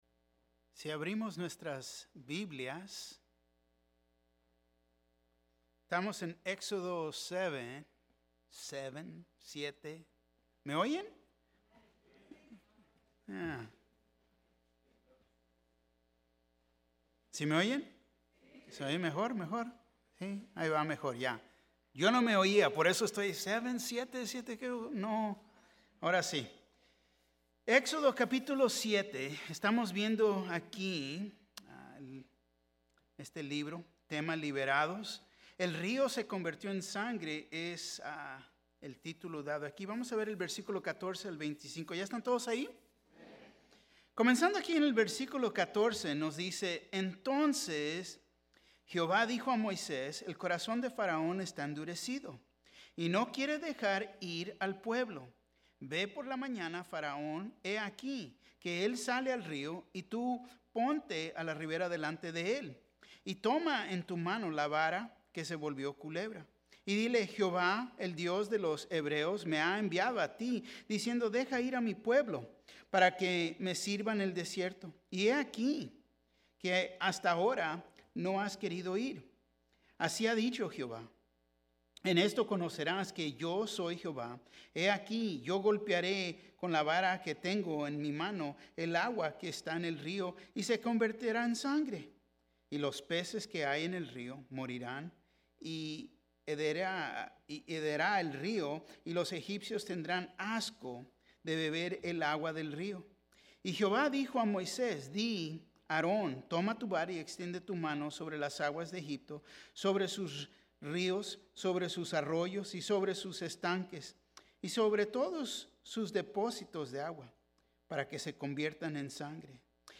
Mensaje
Un mensaje de la serie "El Libro De Apocalipsis." Recuerda cuando los Israelitas endurecieron su corazón, no permitieron que Dios hiciera lo que Él quería hacer con ellos.